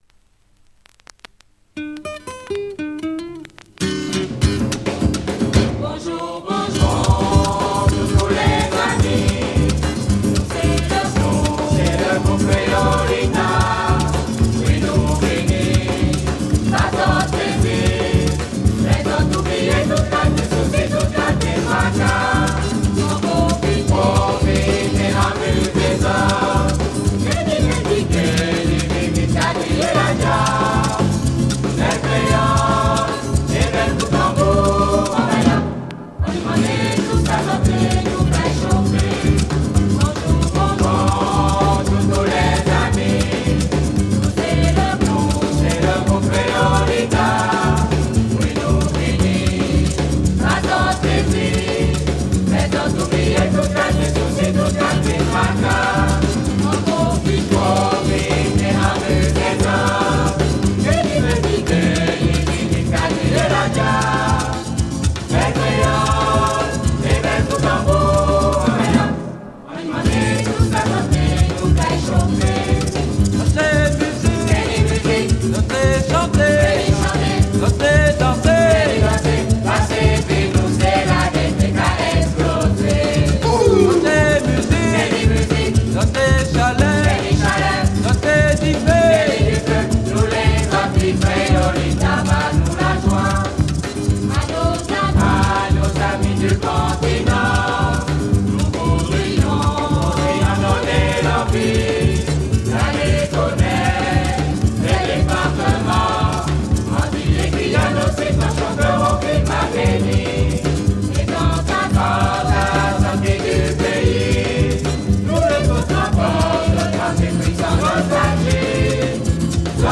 West indies folk ethno EP